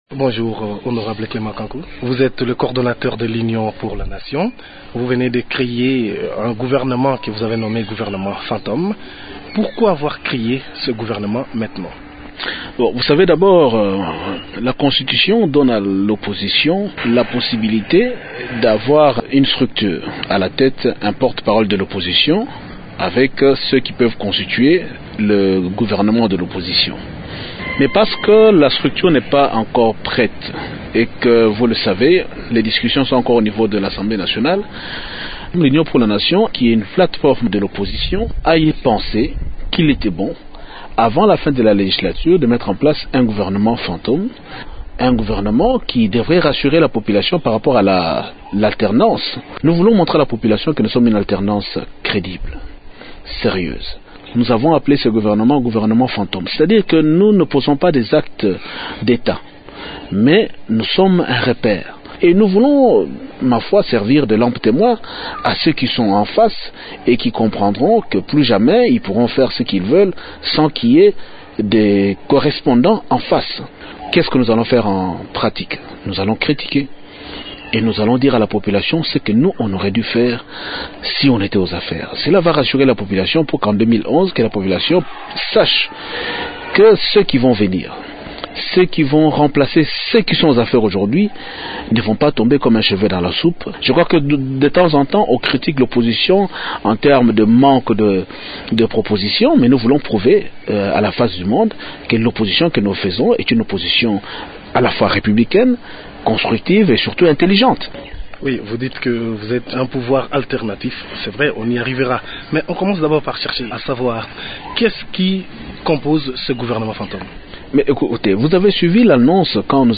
Clément Kanku répond dans cet entretien